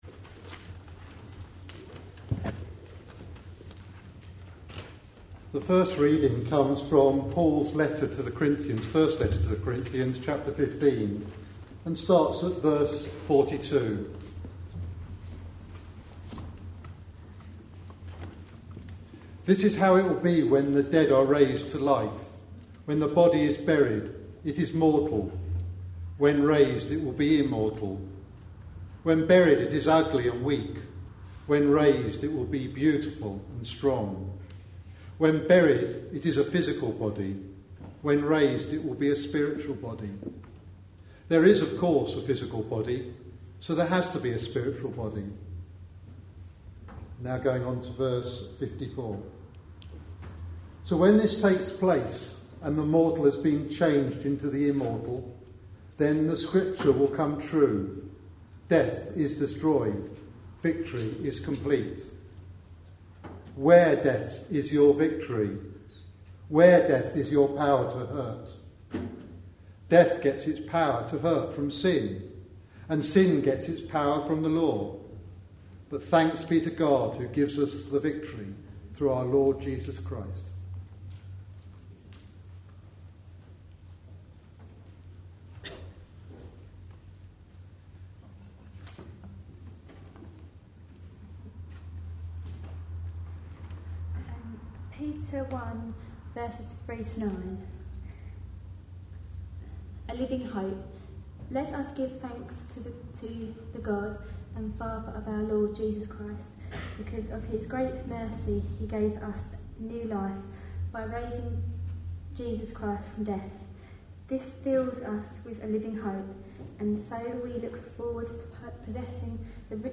A sermon preached on 31st October, 2010, as part of our A Letter to Young Christians series.